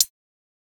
TS HiHat_12.wav